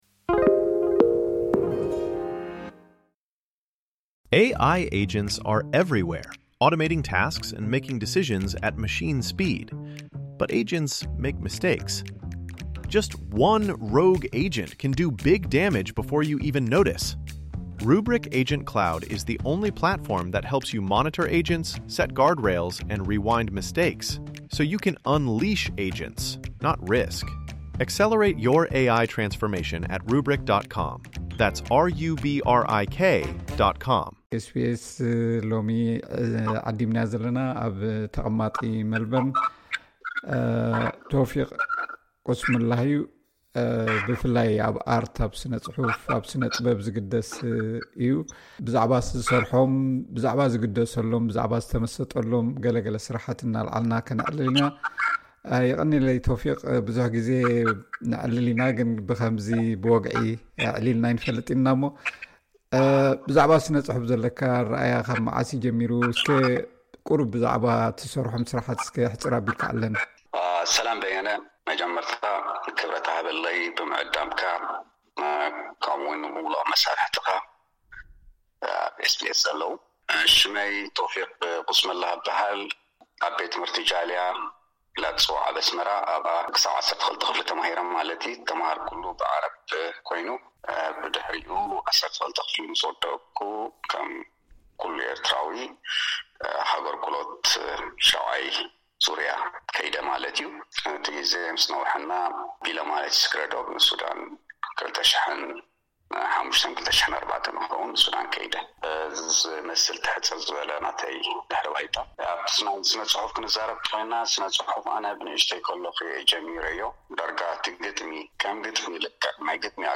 ዕላል ስነ ጽሑፍ